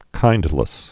(kīndlĭs)